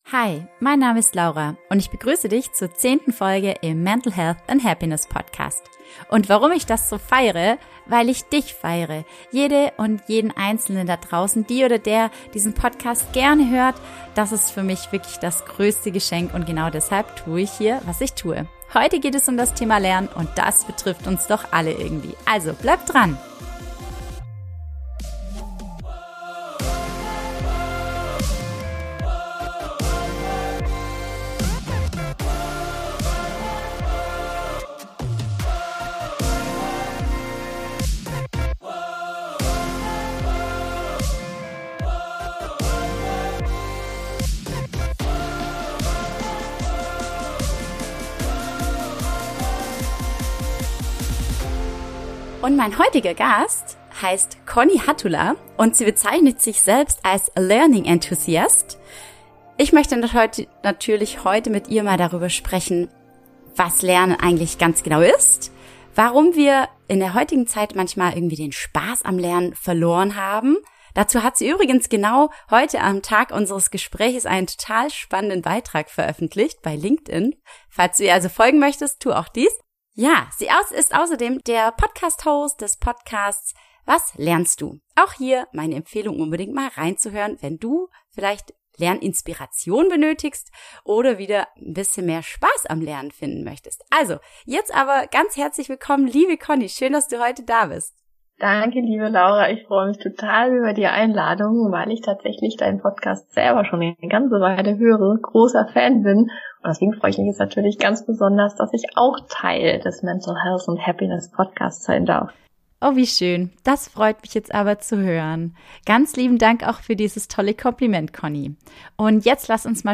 Da kommt Lernfreude auf! - Talk